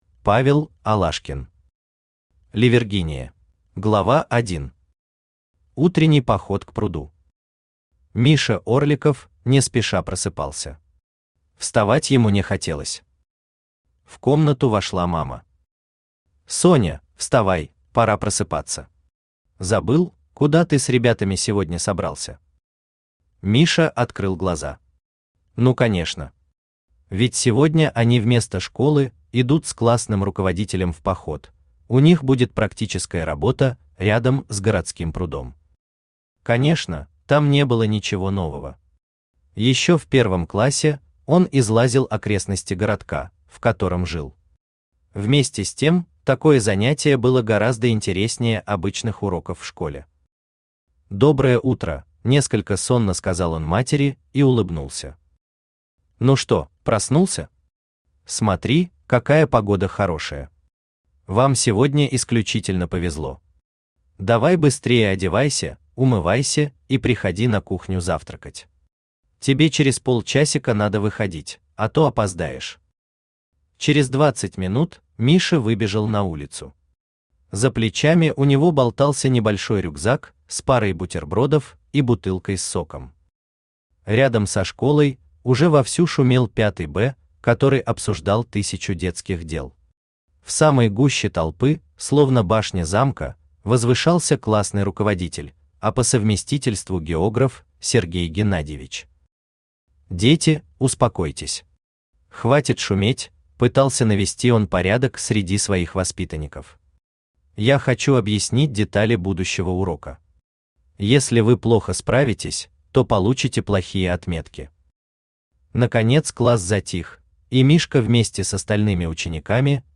Аудиокнига Ливергиния | Библиотека аудиокниг
Aудиокнига Ливергиния Автор Павел Алашкин Читает аудиокнигу Авточтец ЛитРес.